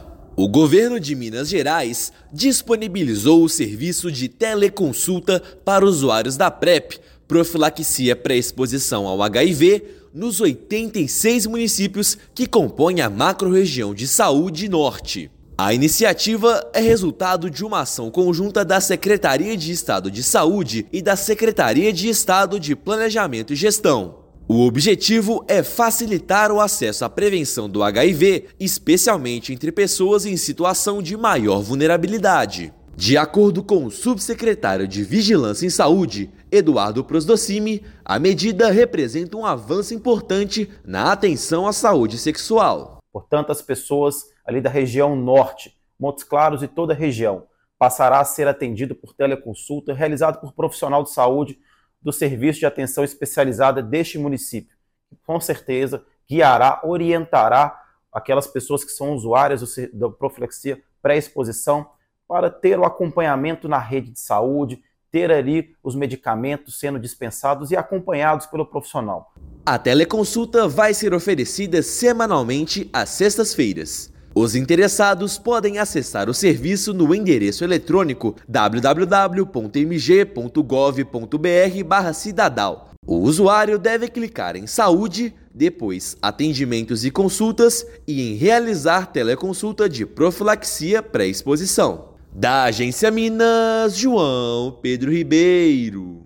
Iniciativa visa ampliar o acesso à prevenção do HIV em 86 municípios, especialmente da população em situação de vulnerabilidade. Ouça matéria de rádio.